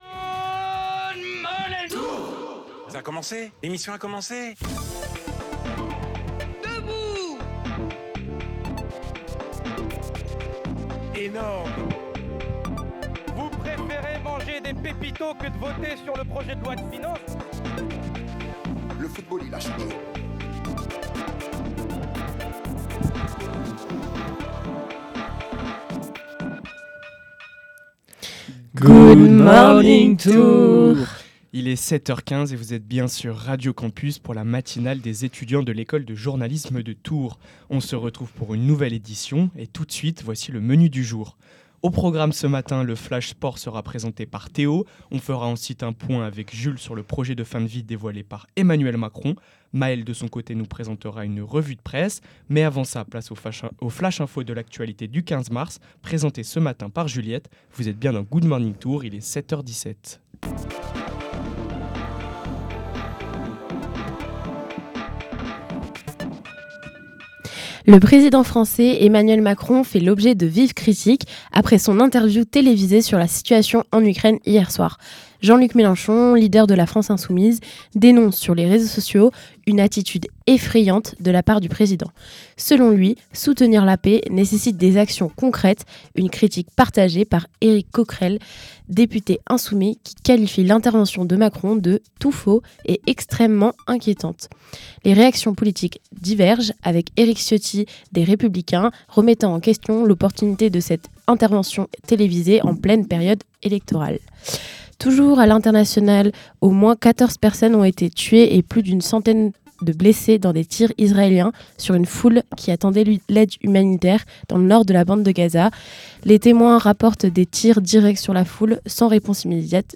La matinale des étudiants de l’École Publique de Journalisme de Tours, le vendredi de 7h15 à 8h15.